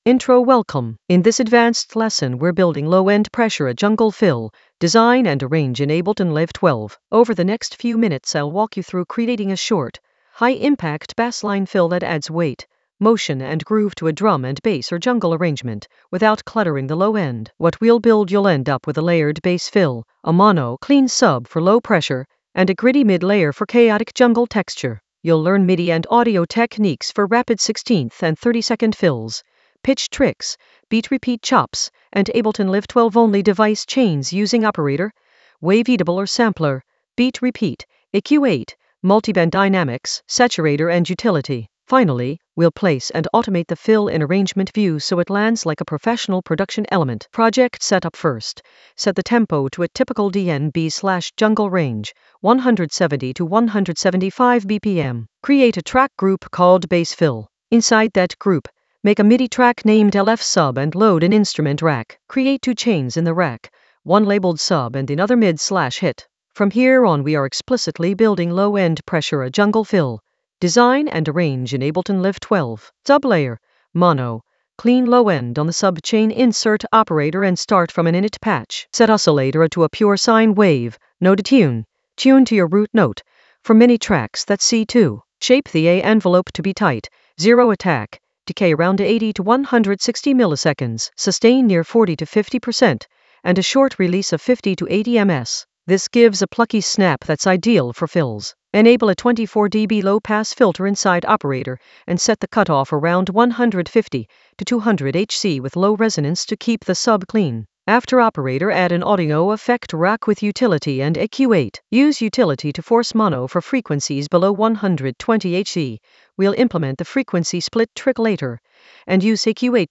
An AI-generated advanced Ableton lesson focused on Low-End Pressure a jungle fill: design and arrange in Ableton Live 12 in the Basslines area of drum and bass production.
Narrated lesson audio
The voice track includes the tutorial plus extra teacher commentary.